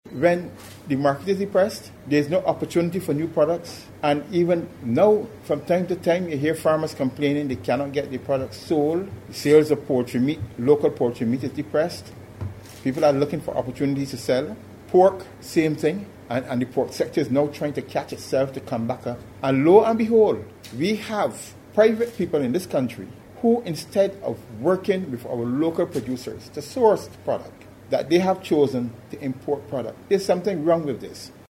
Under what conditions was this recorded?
at news conference this morning